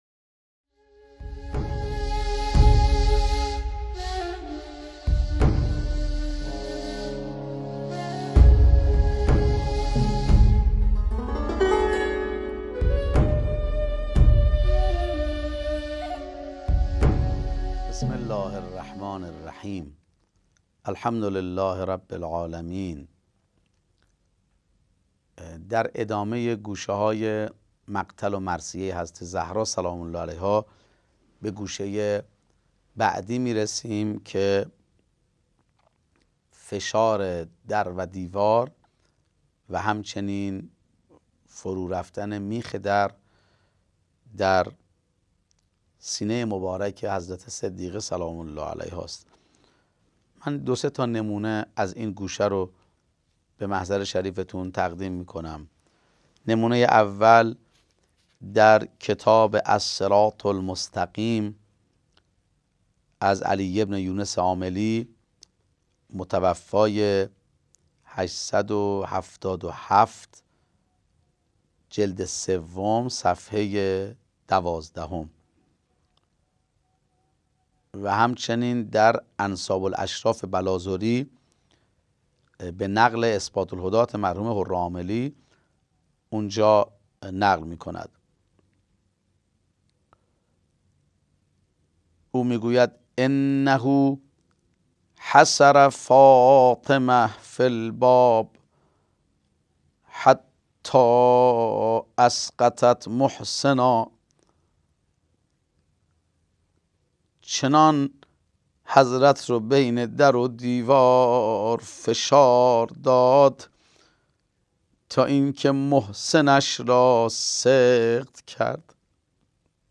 «در سوگ خورشید» مجموعه درس‌گفتارهای